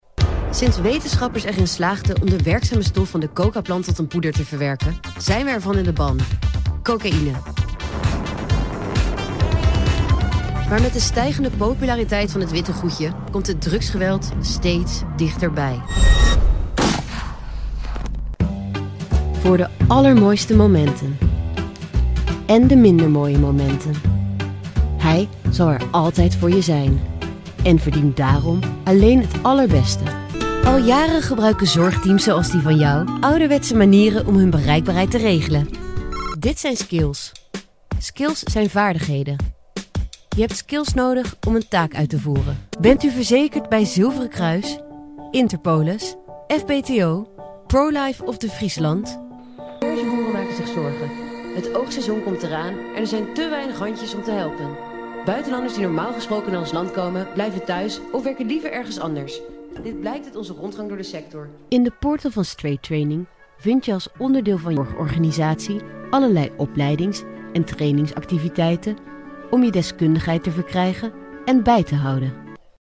女荷02 荷兰语女声 广告MG动画叙述讲述 温柔沉稳 沉稳|科技感|积极向上|时尚活力|神秘性感|亲切甜美|素人